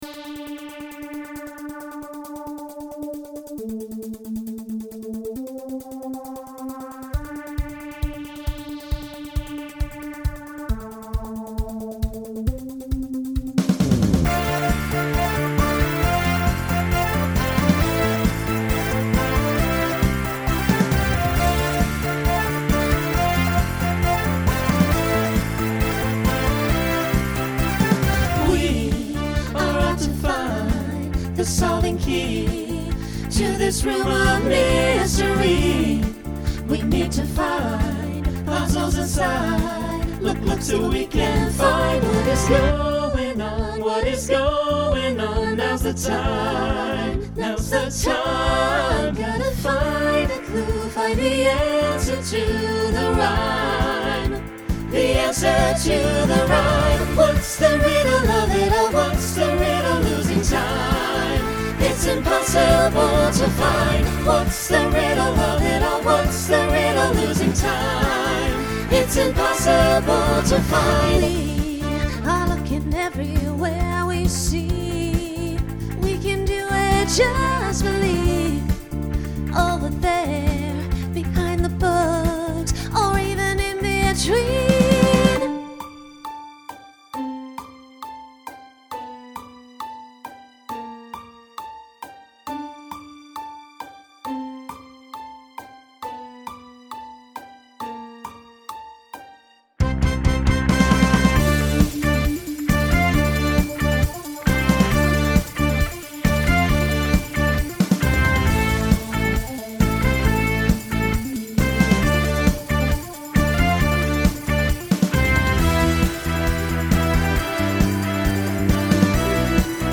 Genre Pop/Dance
Story/Theme Voicing SATB